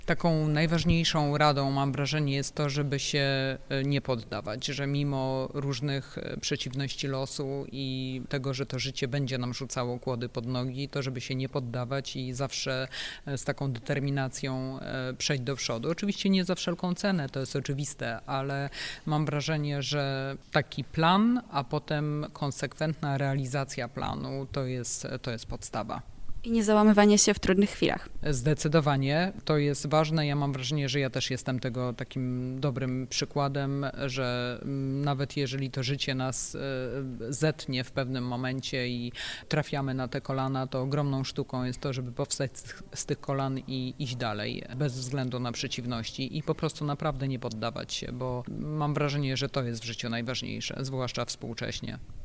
We wtorkowy poranek (20 maja) odbyło się kolejne spotkanie z cyklu „Śniadanie Mistrzów PWT”.